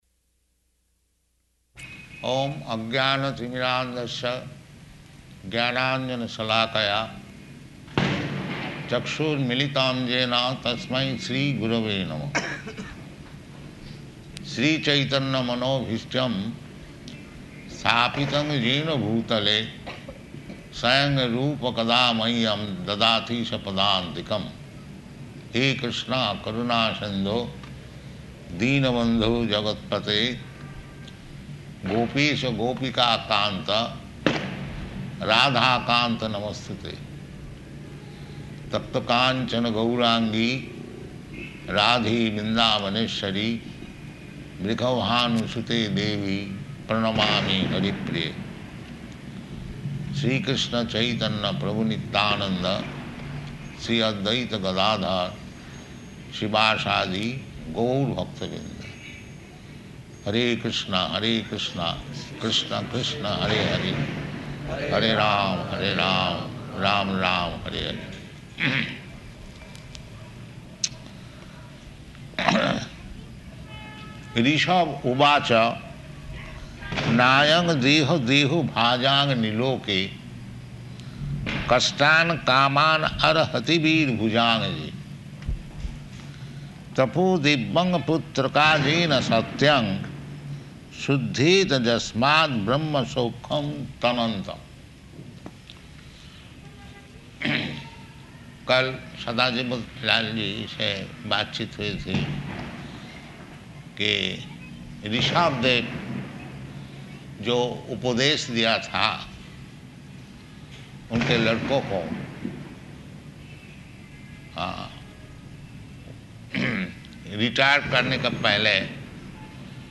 Type: Srimad-Bhagavatam
Location: Bombay